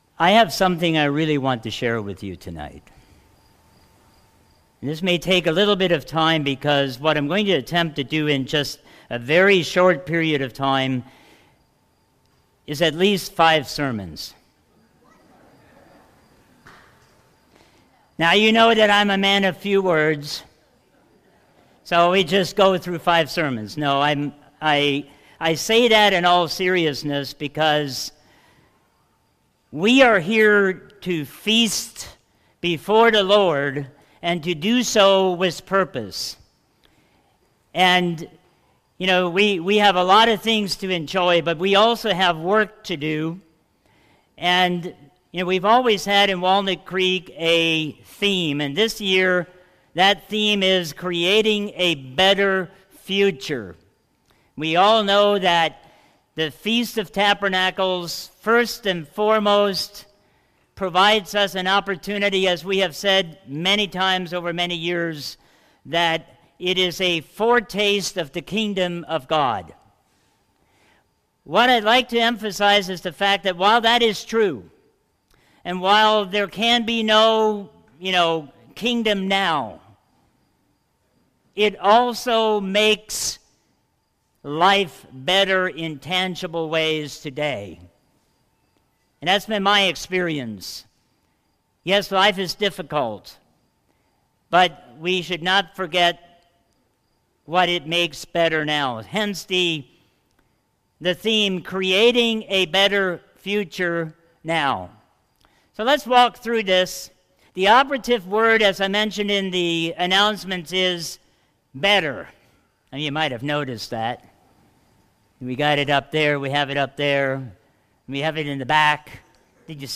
Given in Walnut Creek, Ohio